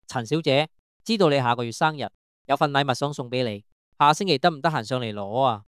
按此 : 收聽 項目例子 (2) _ AI 語音